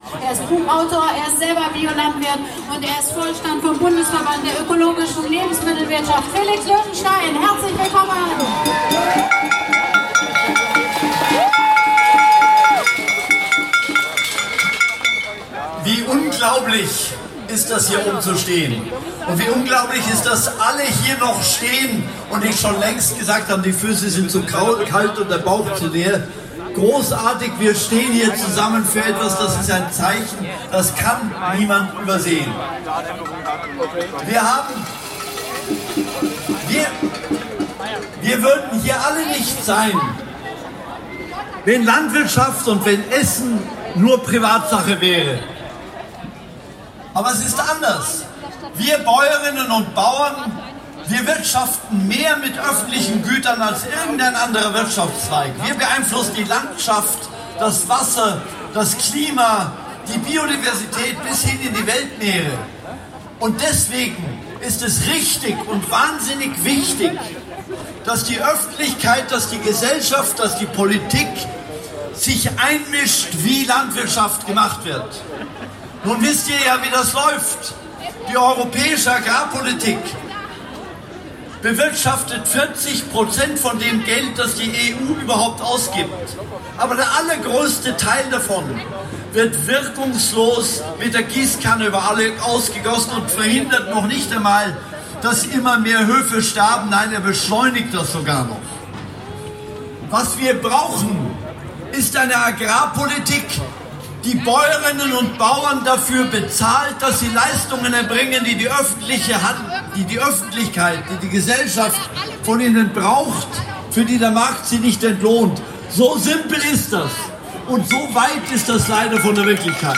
Eindrücke und Ansprachen der Abschlußkundgebung